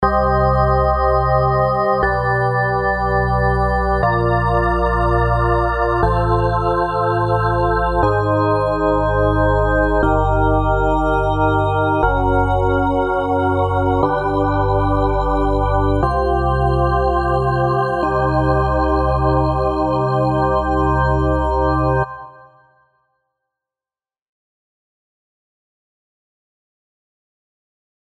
前回に引き続きエレクトリックオルガン音色の作成ポイントを解説していきます。
マトリックス機能を使用してコーラスエフェクトのかかり具合をリアルタイムコントロールしています。
作成中にフィルターなども設定してみたのですが、元々サイン波合成で倍音構成がシンプルであるため、あまり効果的な音色調整が行なえなかったことから、思い切ってフィルターは使用せずに音色を仕上げることにしました。
改めて実際のエレクトリックオルガンの音をじっくり聴き込んでみると、音色の設定によっては「カツッ」、あるいは「ポコッ」というようなアタック感があるのがわかります。